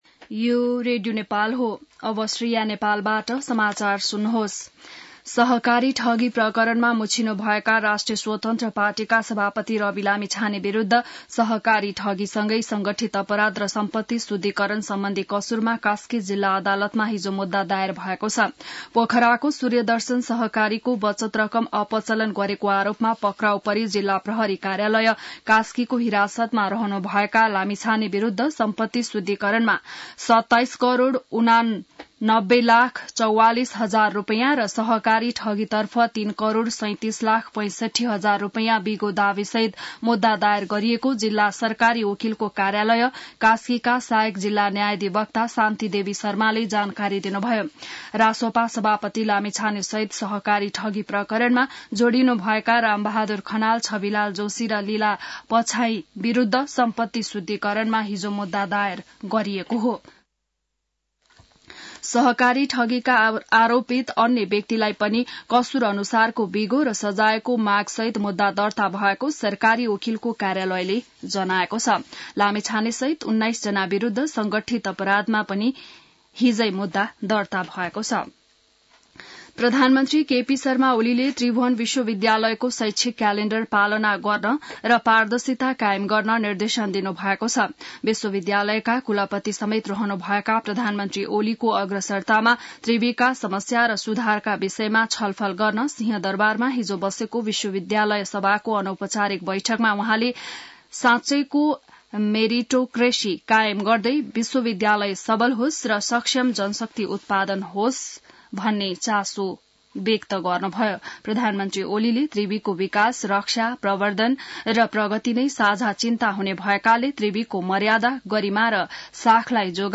बिहान ६ बजेको नेपाली समाचार : ९ पुष , २०८१